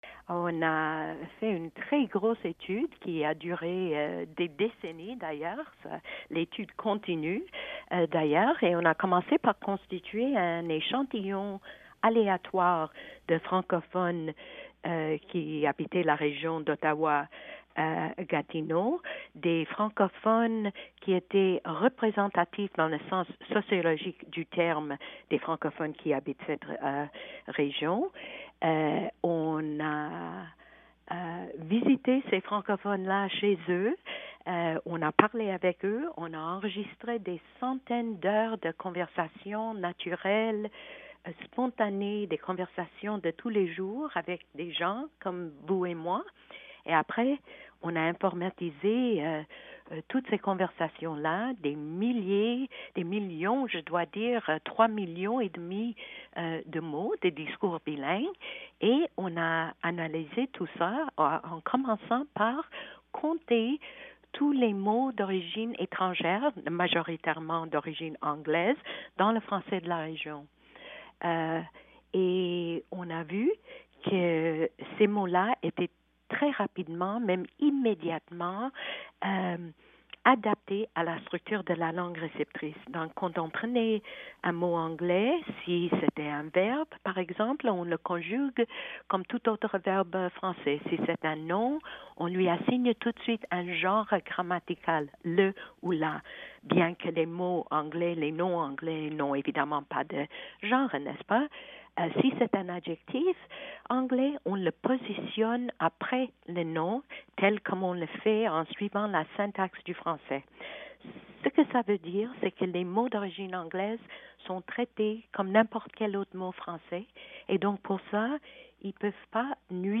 Nous avons parlé avec la professeure pour qu’elle nous explique comment elle et son équipe sont arrivées à ces conclusions.